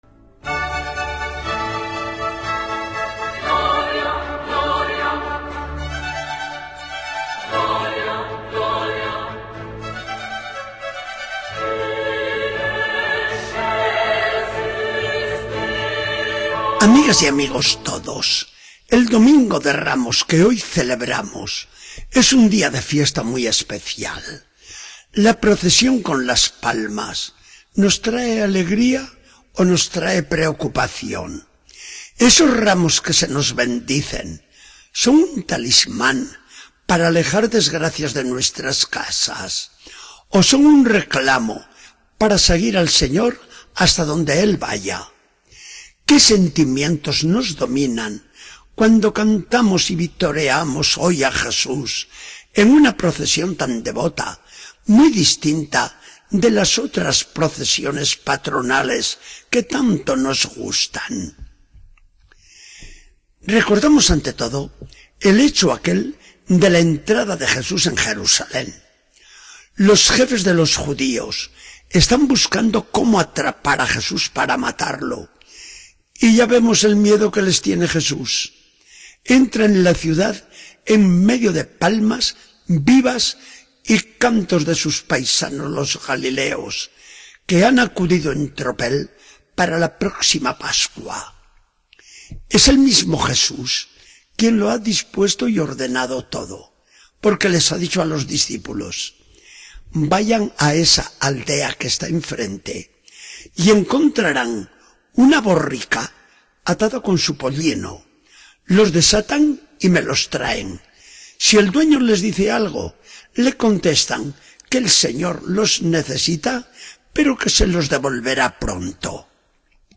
Charla del día 12 de abril de 2014.